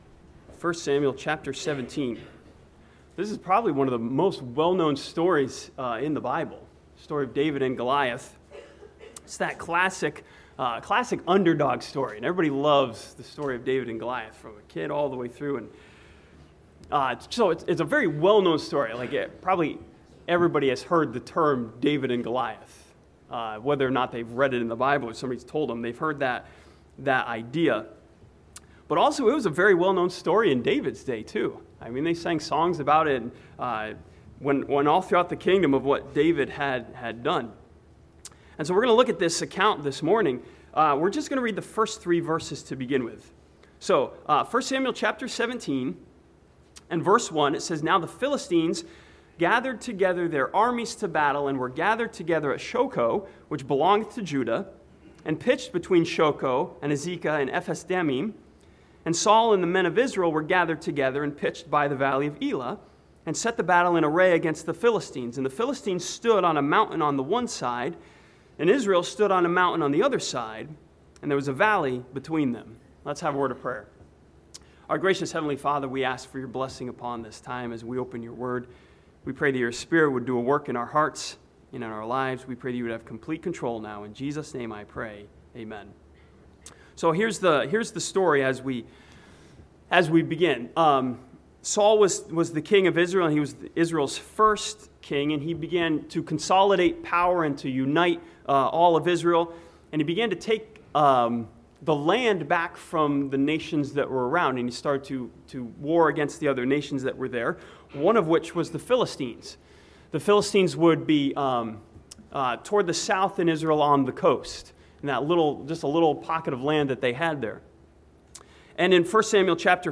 This sermon from 1 Samuel chapter 17 studies the battle between David and Goliath and sees a crucial fight before the battle.